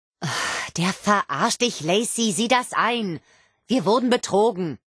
Kategorie:Wastelanders: Audiodialoge Du kannst diese Datei nicht überschreiben.